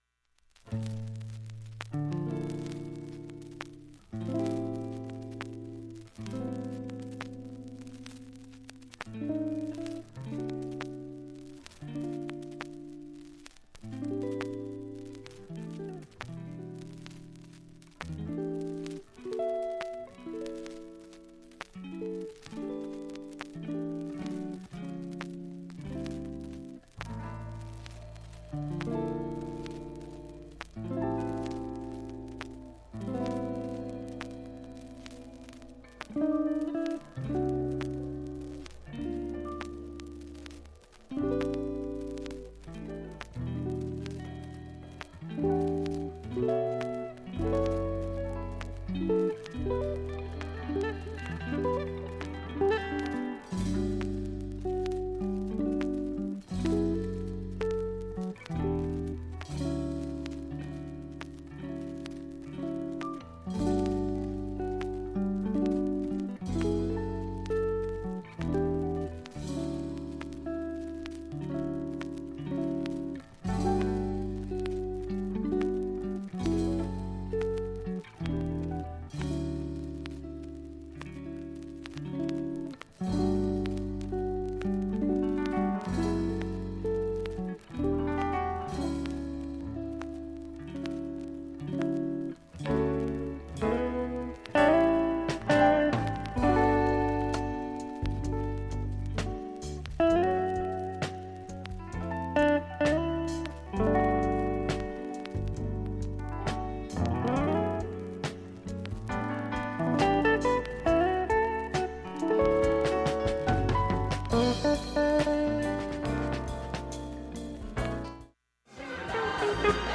曲間も結構クリアでいい音質です。
音質良好全曲試聴済み。
A-１始め40秒静かな部で、軽い周回サーフェスと
２分の間に周回プツ出ますがかすかです。
メロウジャズファンク